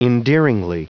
Prononciation audio / Fichier audio de ENDEARINGLY en anglais
Prononciation du mot : endearingly